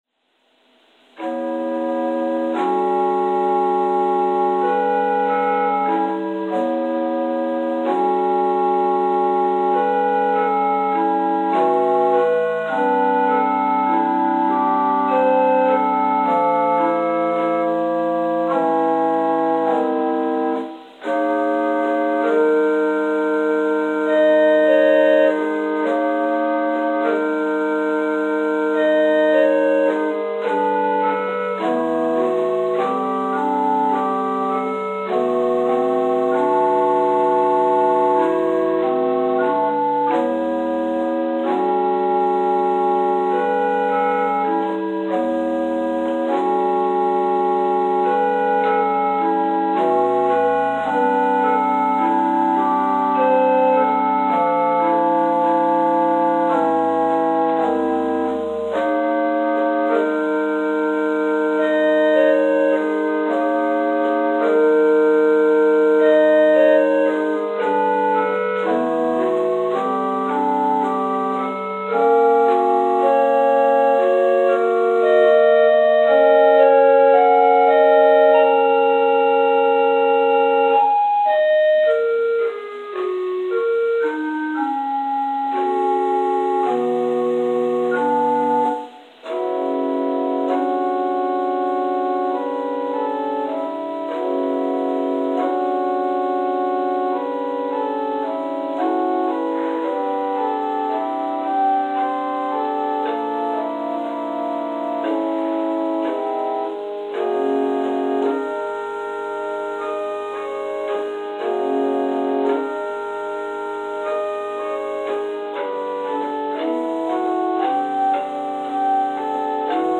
Interlude: “Elegie” – David Paxton